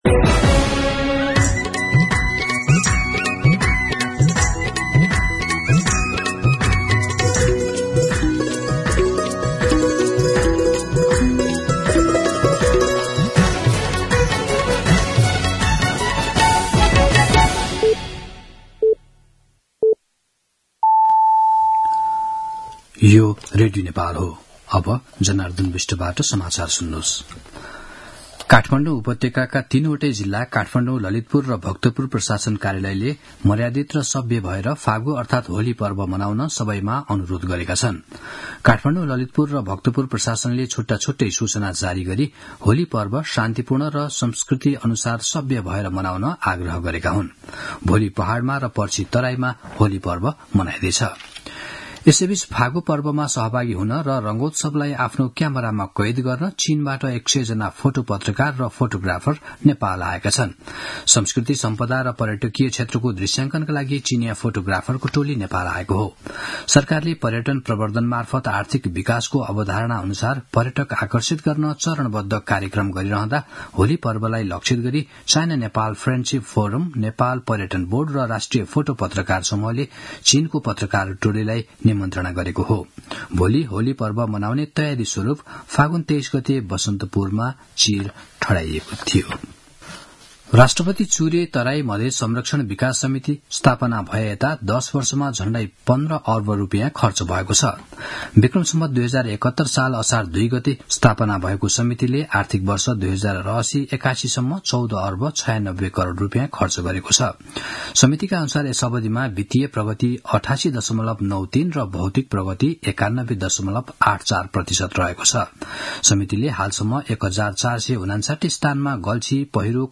मध्यान्ह १२ बजेको नेपाली समाचार : २९ फागुन , २०८१